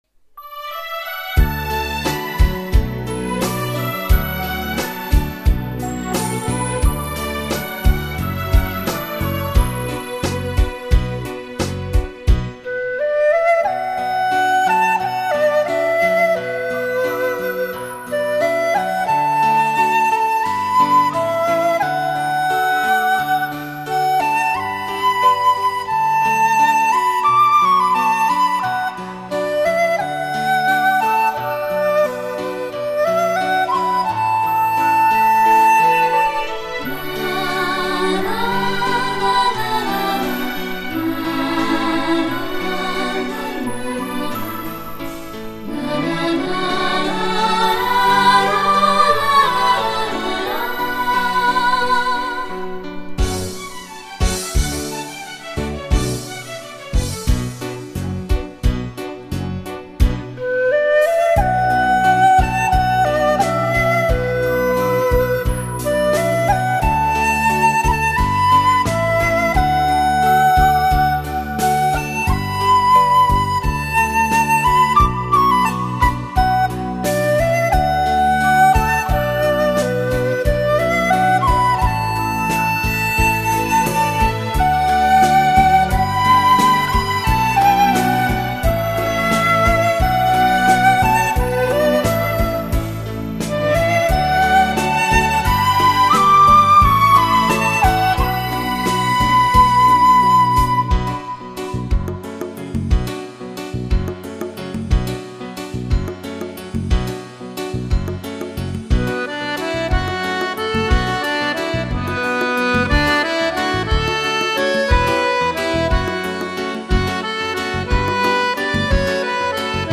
竹笛、箫、弦乐，人声
一种典型而时尚的绿色音乐，清纯婉回，淳朴脱俗，置身其中，无色无欲，让你远离城市的喧嚣，回归自然。